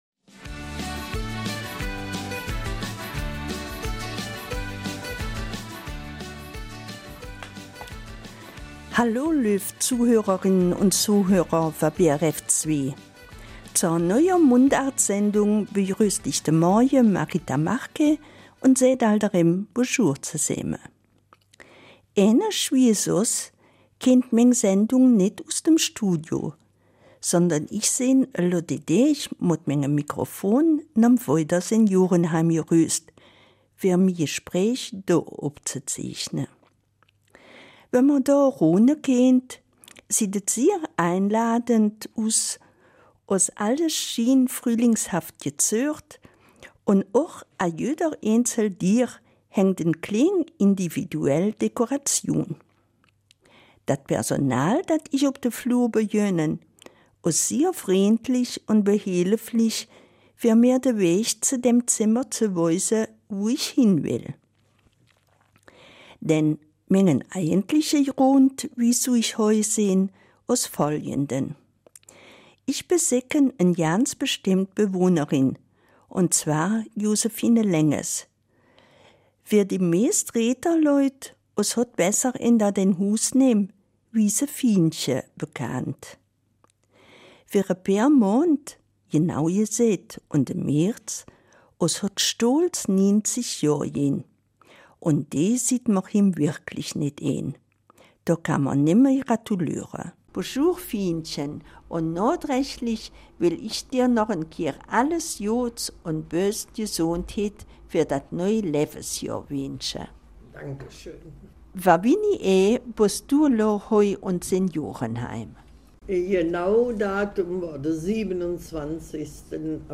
Eifeler Mundart: 90.